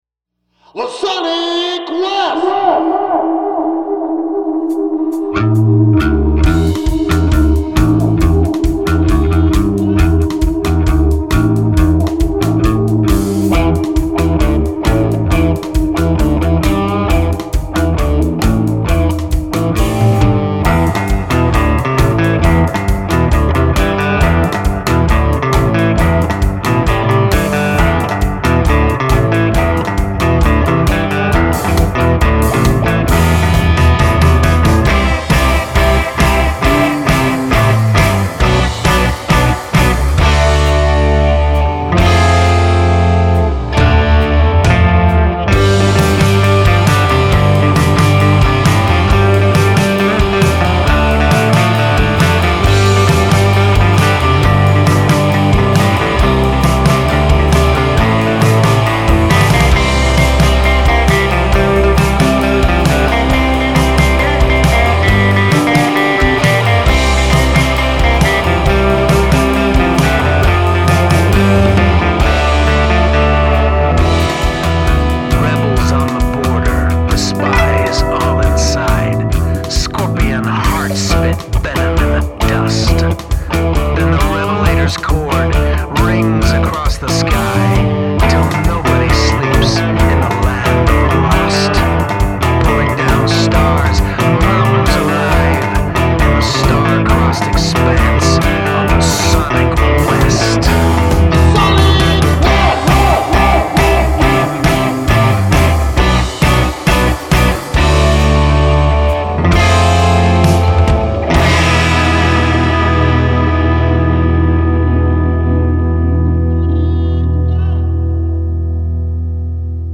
Bass
Drums
add an old Western radio monologue